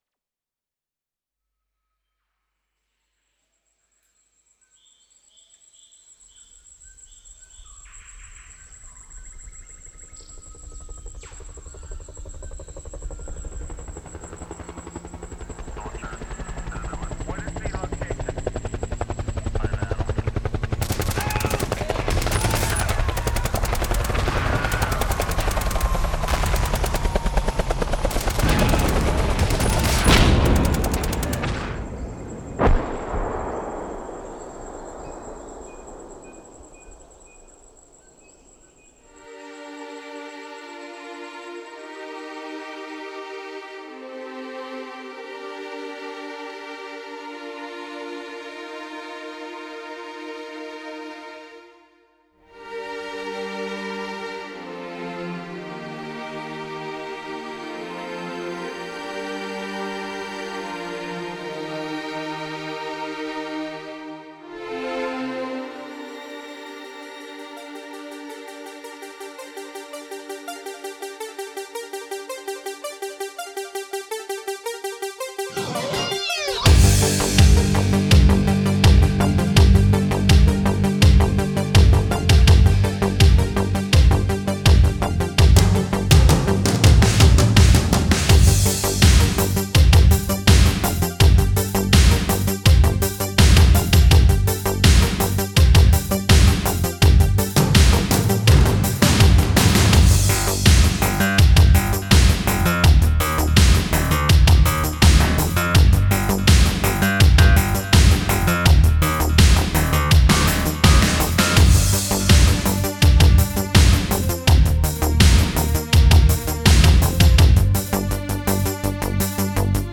Каналы: 2 (joint stereo)
Жанр: Game